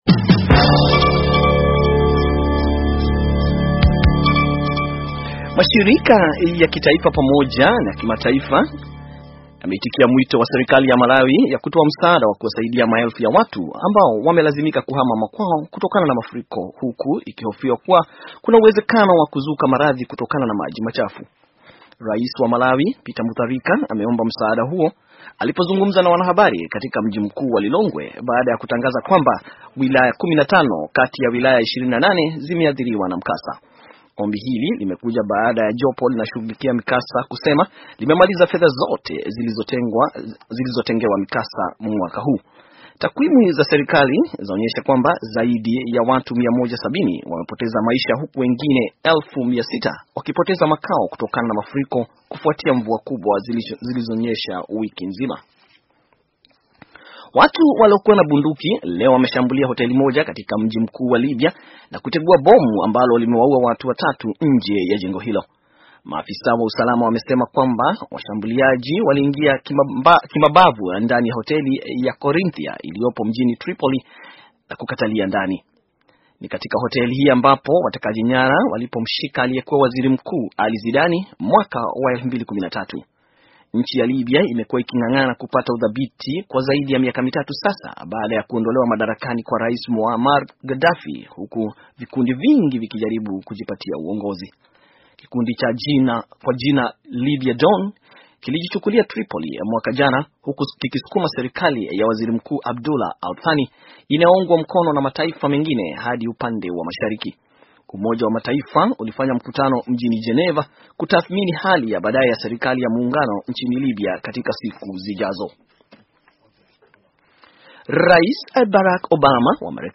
Taarifa ya habari - 3:20